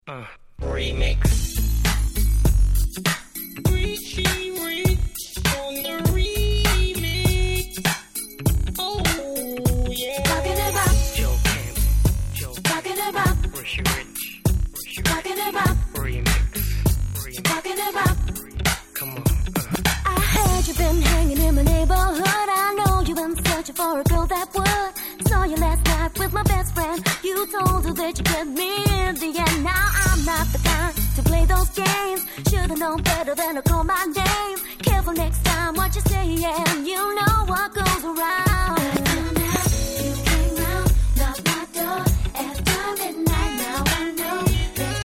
※試聴ファイルは別の盤から録音してございます。
01' Nice UK R&B !!
フロアが温かな雰囲気になりますよね〜(^o^)
鉄板キャッチークラシック！